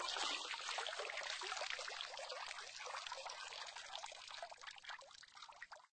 water_rising.ogg